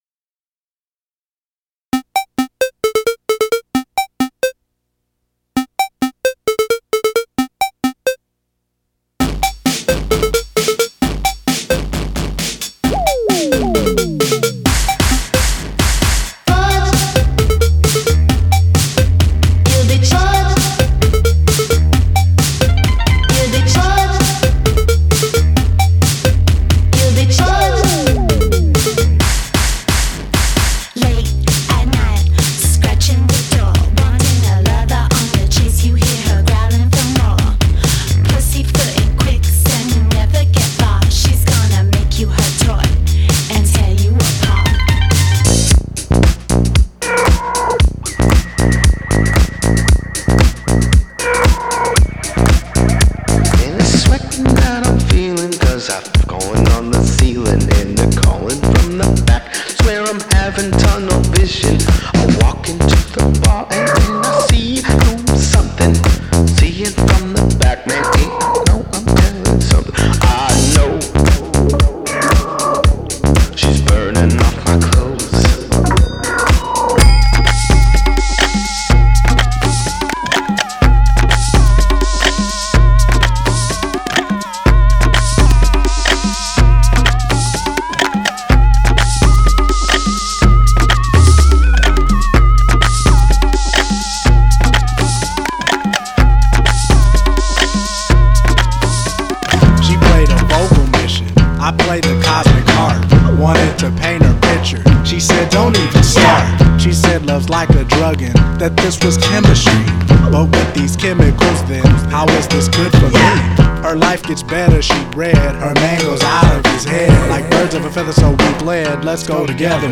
petite demo